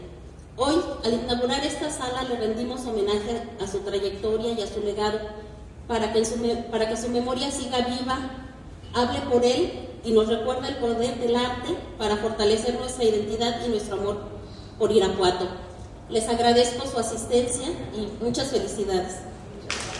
AudioBoletines
Araceli Beltrán Ramírez, regidora